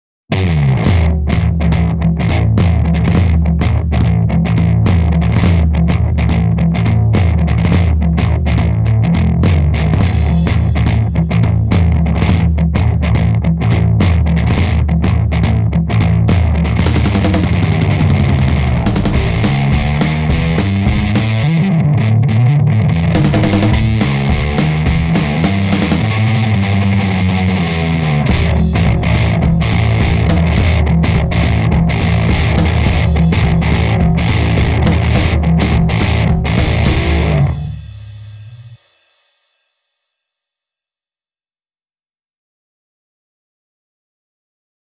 Autres noms du produit:BOSS OD-20 DriveZone guitar effect pedal, BOSS OD-20, BOSS OD 20, BOSS OD20, OD-20, OD 20, OD20
BOSS OD-20 - bass